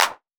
Urban Clap 02.wav